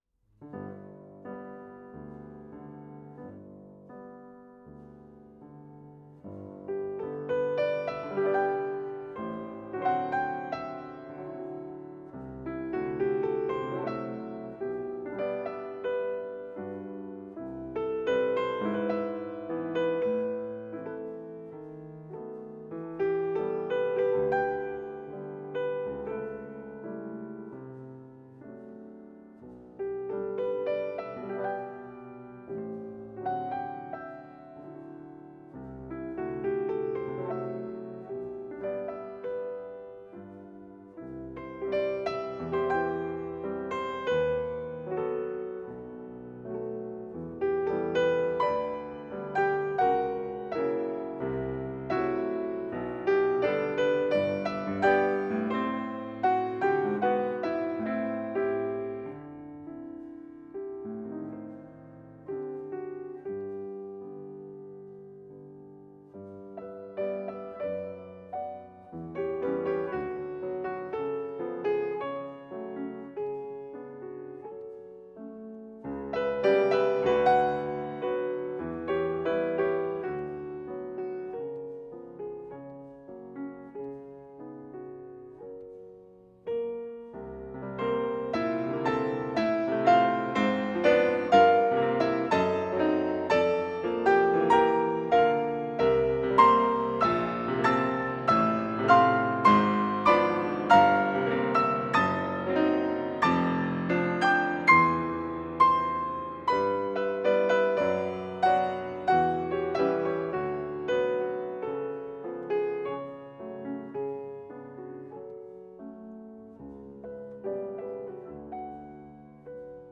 Classical Elgar, Edward Carissima Cello version
Cello  (View more Intermediate Cello Music)
Classical (View more Classical Cello Music)